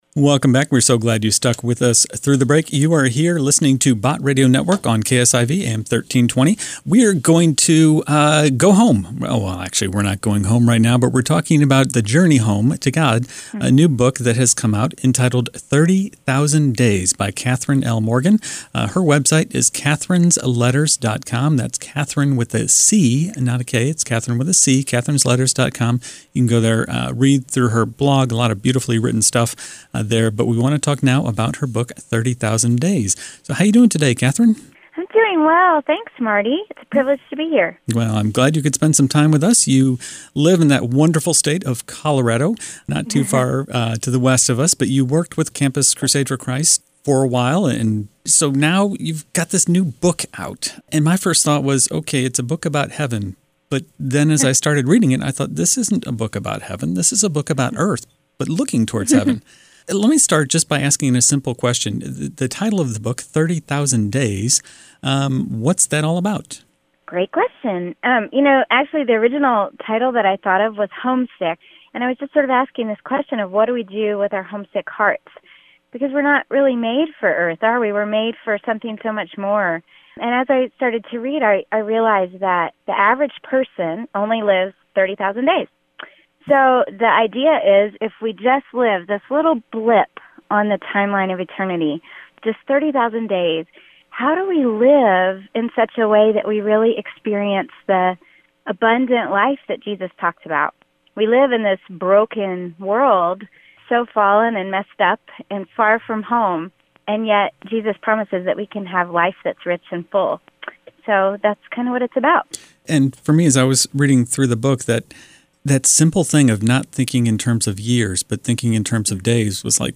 And here’s a radio interview: